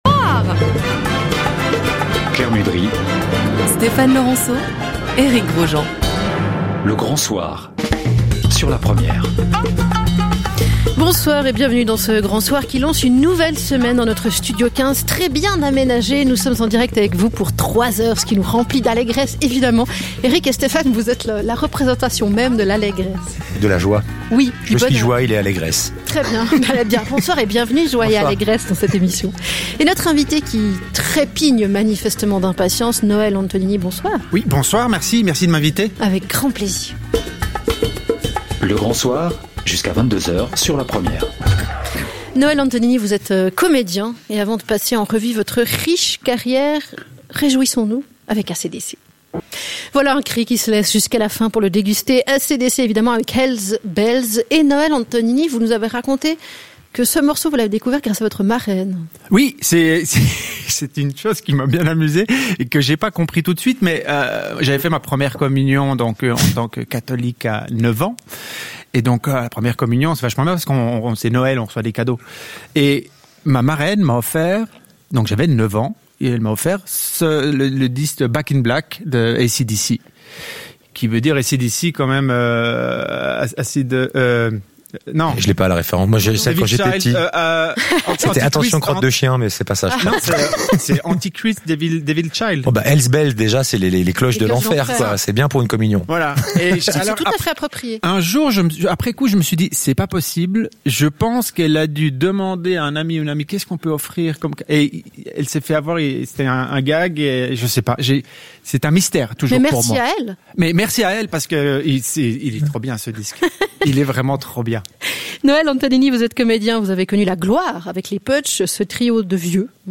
Interview "Le Grand Soir" RTS-La Première, 7 mars 2022